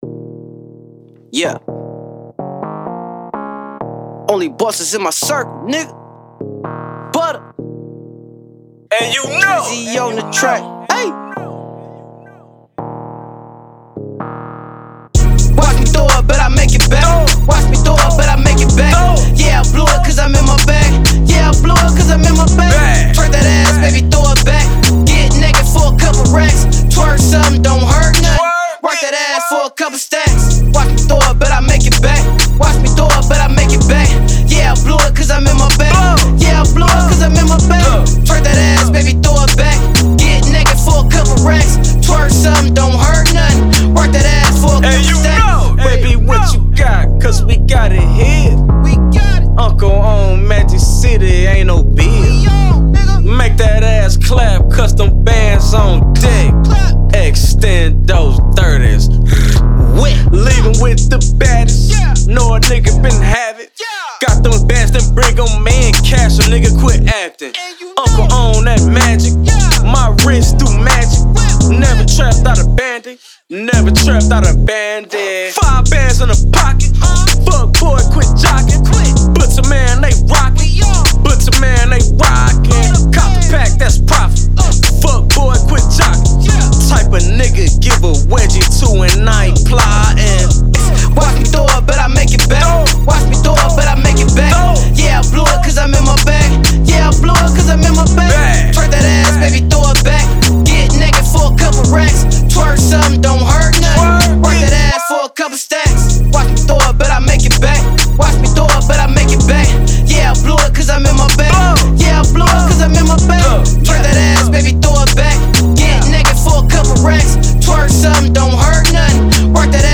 Indie
strip club anthem